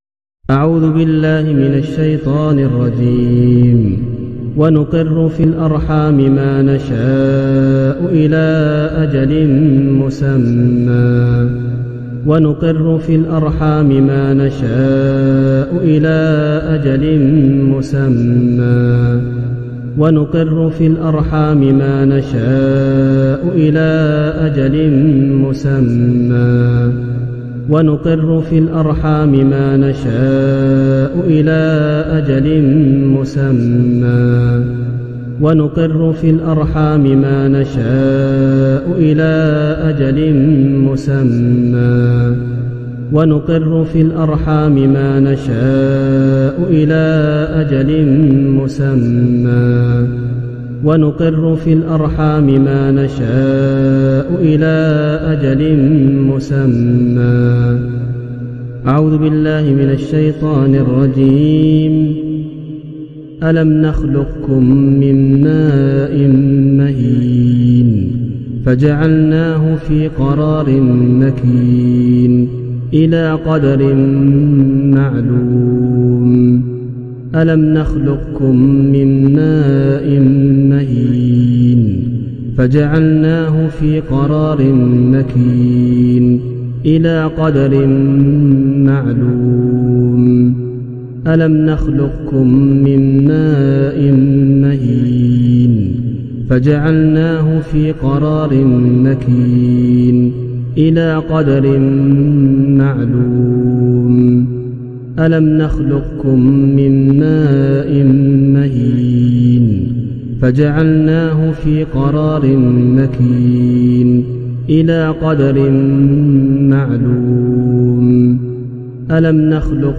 গর্ভ রক্ষার রুকইয়াহ